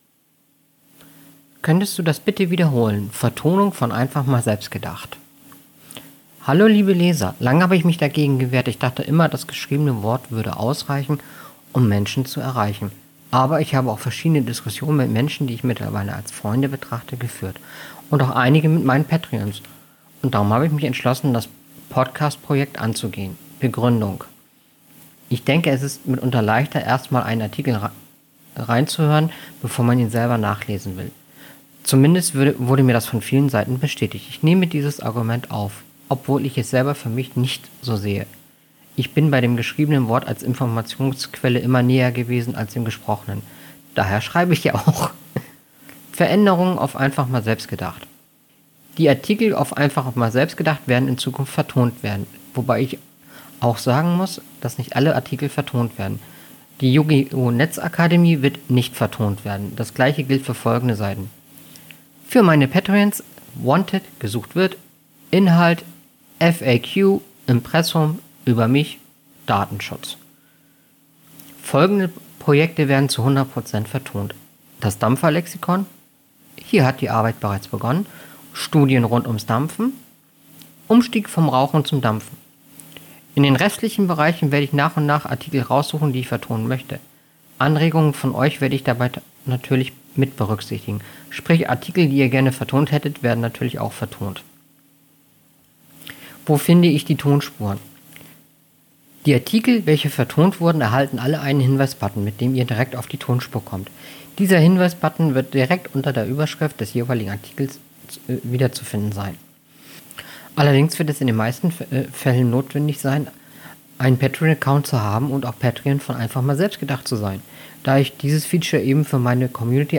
Hier bei handelt es sich um die vertonte Spur des Artikels: